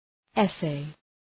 Shkrimi fonetik {e’seı}